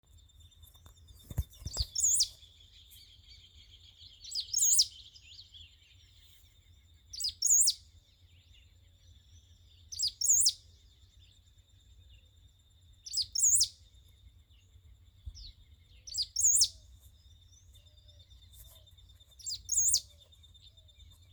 Pampa Finch (Embernagra platensis)
Detailed location: Colonia Ayuí, Paso del Águila
Condition: Wild
Certainty: Observed, Recorded vocal